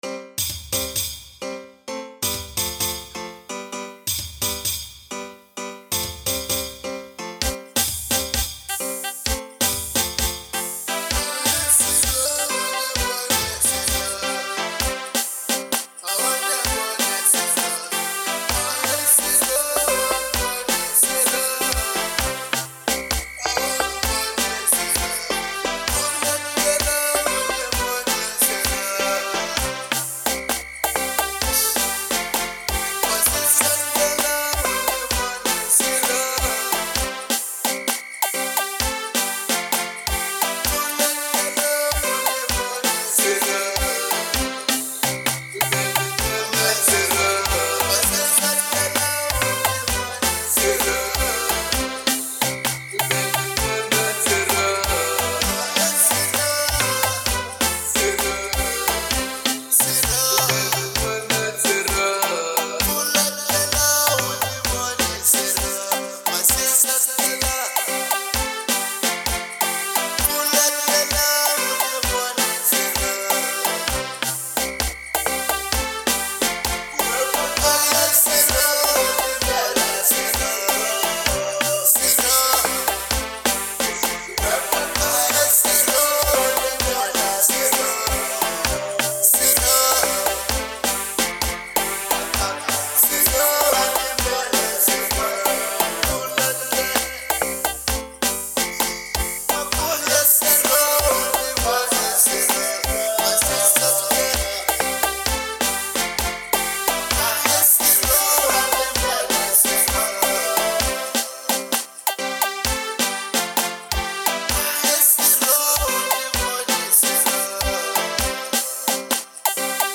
03:20 Genre : Xitsonga Size